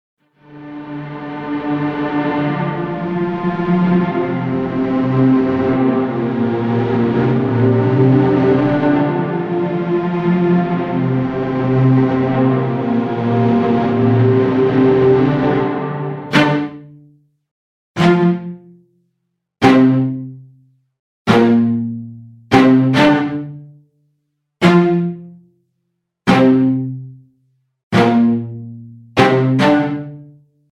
UltraTap | Strings | Preset: UltraSwell
Strings-UltraSwell-Wet-Dry.mp3